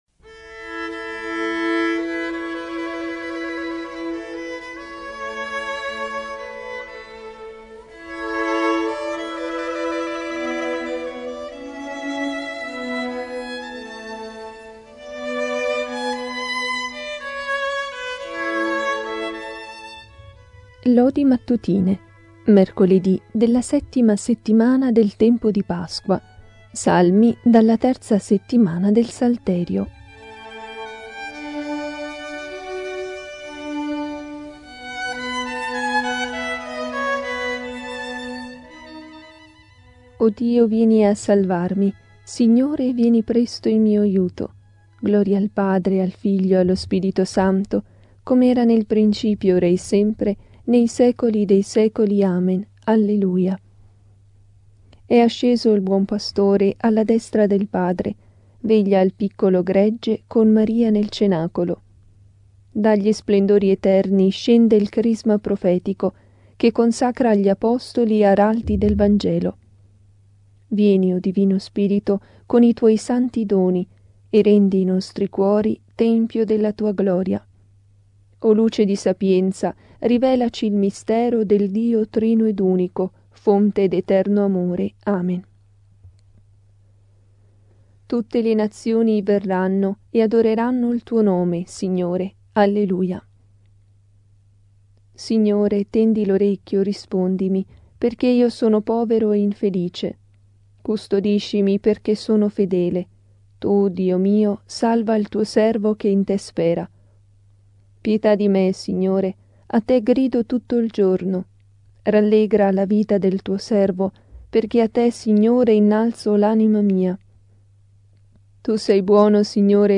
Genere: Lodi di Pasqua.